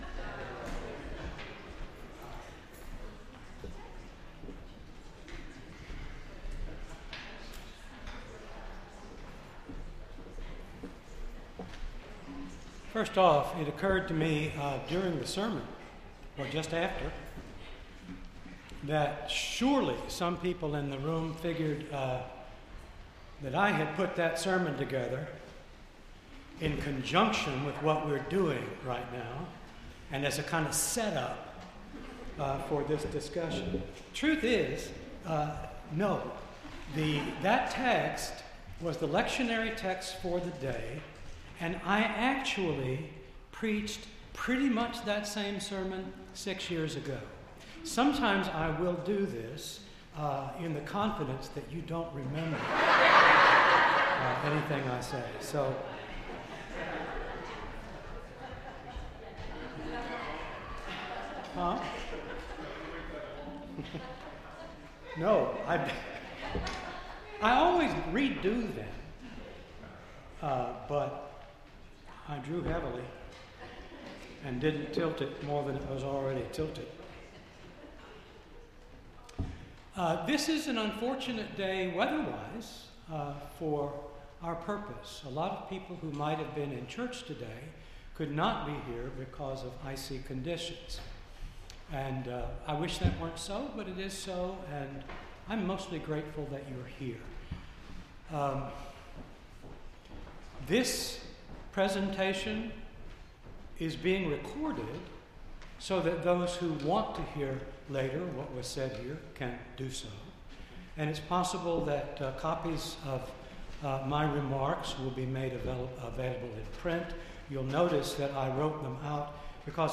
First Baptist Church, Ann Arbor
4-15-18-second-hour-presentation.mp3